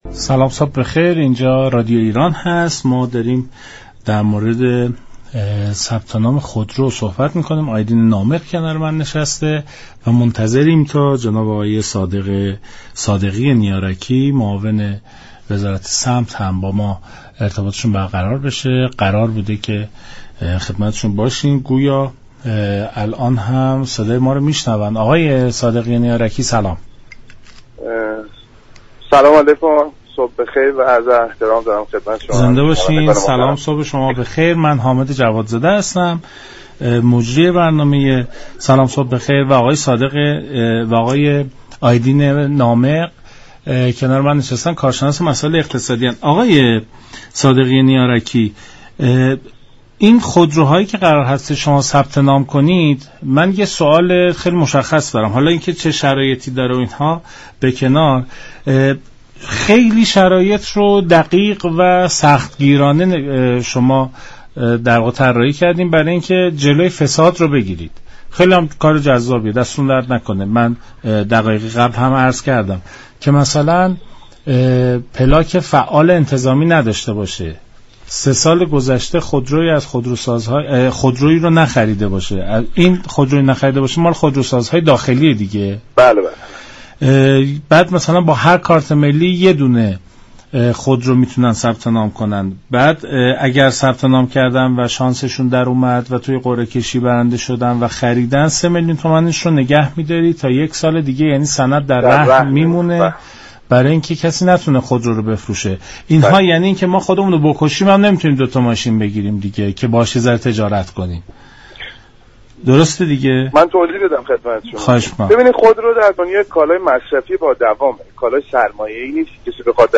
به گزارش شبكه رادیویی ایران، مهدی صادقی نیاركی معاون امور صنایع وزارت صمت در برنامه «سلام صبح بخیر» رادیو ایران درباره جزئیات طرح ساماندهی بازار خودرو گفت: در تمام دنیا خودرو كالای مصرفی و با دوام است، و ما در طرح ساماندهی فروش خودرو تلاش می كنیم خودرو دوباره به حالت قبل خود (كالای مصرفی با دوام) بازگردد.